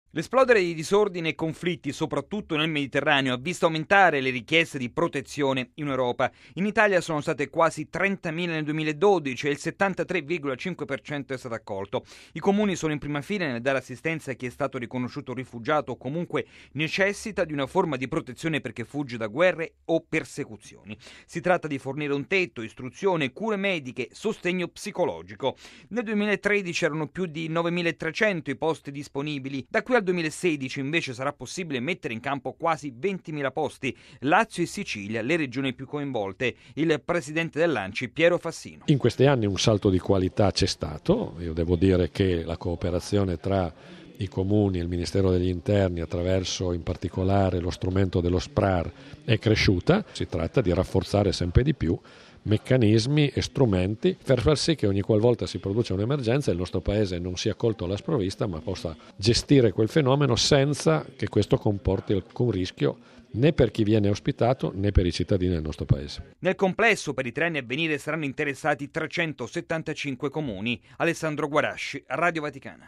Per il presidente dell'associazione Piero Fassino, però, il sistema ha bisogno di sempre maggiori risorse. Il servizio